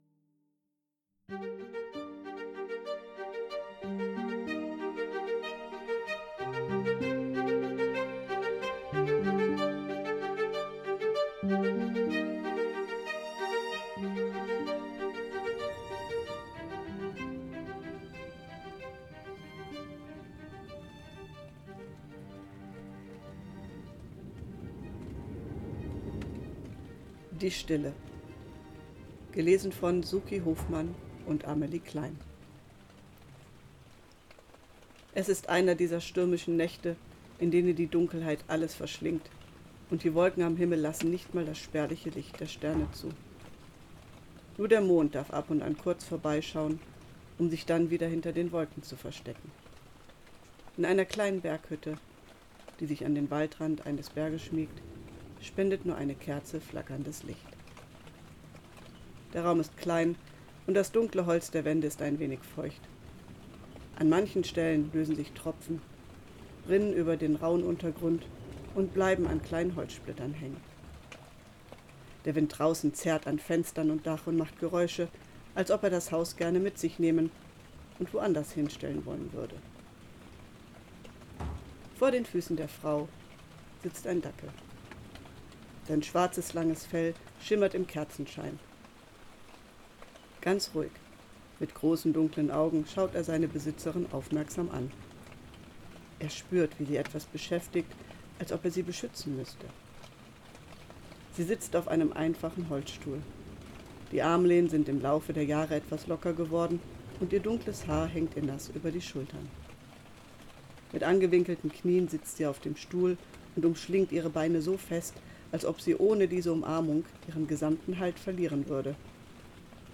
Die Stille (Hörspiel)
die-stille-hoerspiel.mp3